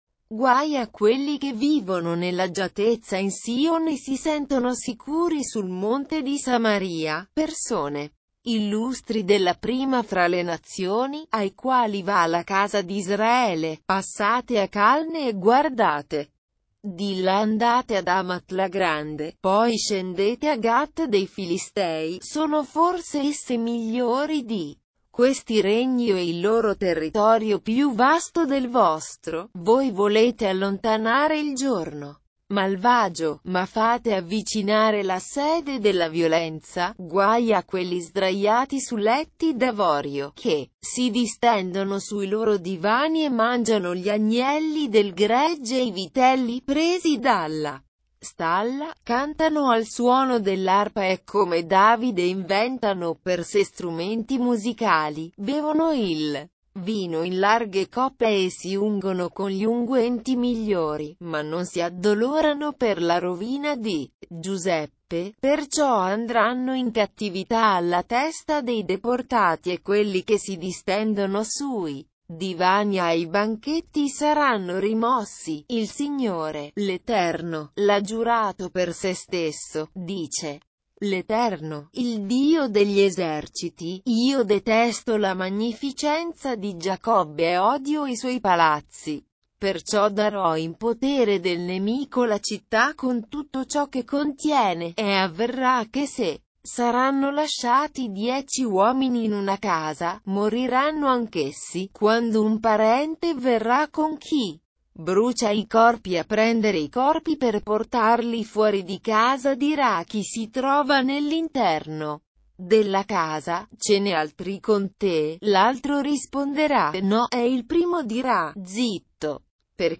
Leitura na versão LND - Italiano